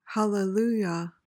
PRONUNCIATION:
(hal-uh-LOO-yuh)